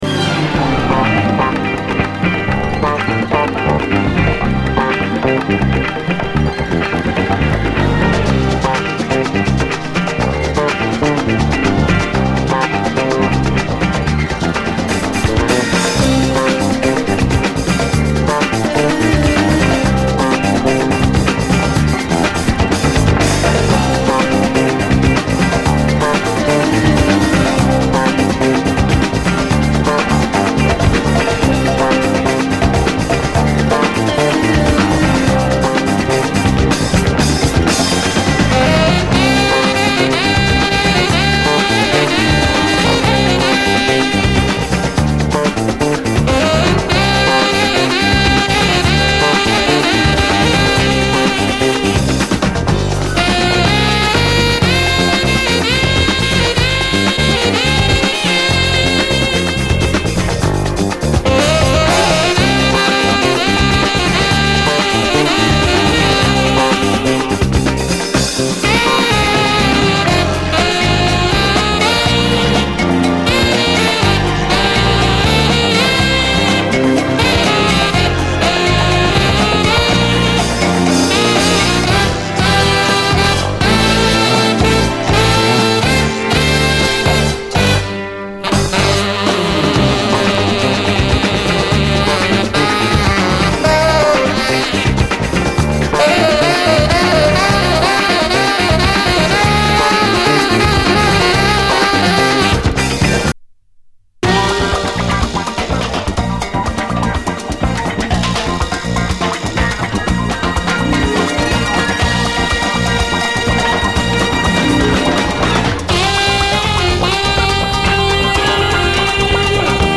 JAZZ FUNK / SOUL JAZZ, JAZZ
トロピカル・ジャズ・ファンク